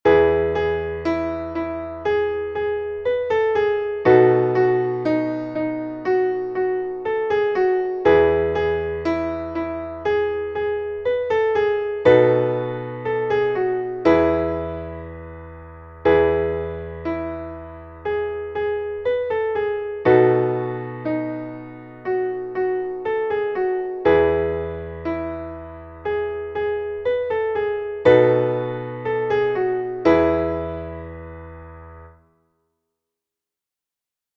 Traditionelles Kinderlied
Spiritual